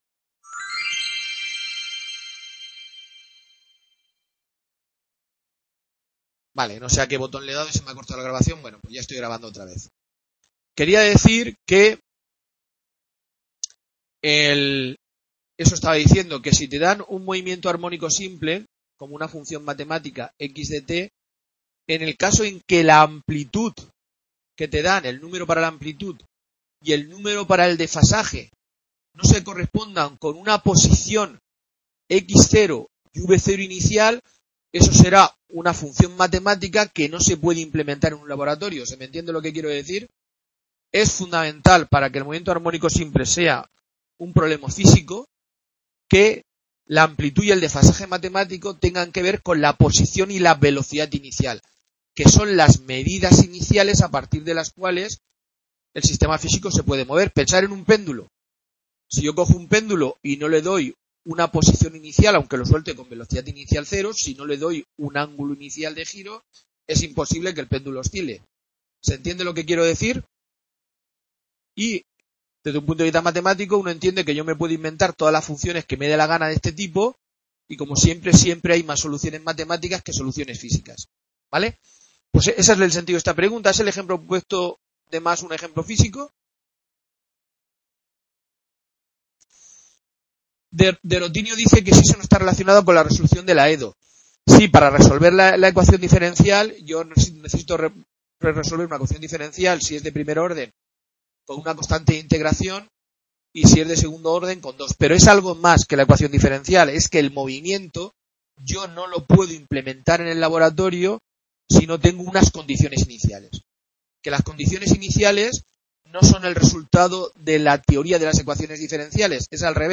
Tutoría virtual 1, parte 2/3, relativa al tema 1 de la asugnatura del grado en Física, Vibraciones y Ondas. Cinemática del m.a.s, y superposición de vibraciones.